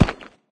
grassstone3.ogg